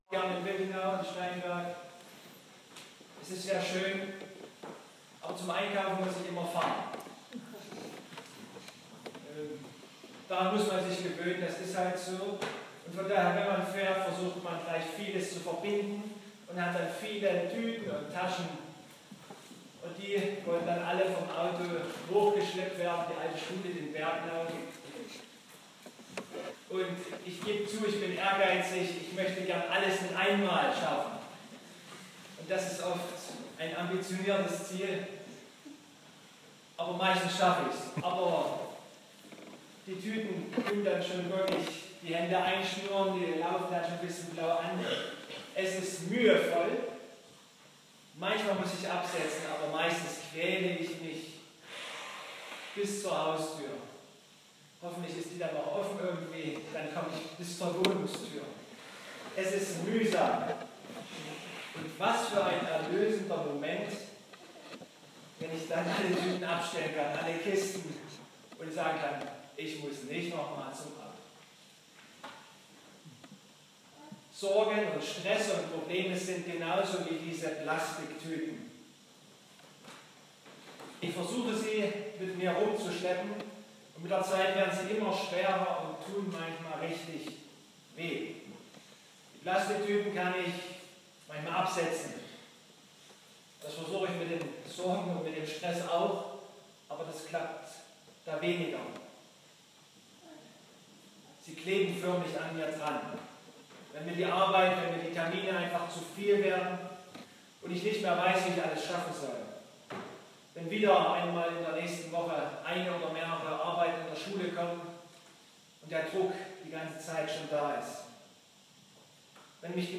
Mt 11,25-30 Gottesdienstart: Predigtgottesdienst Obercrinitz Zu Kantate singt Jesus uns ein Lied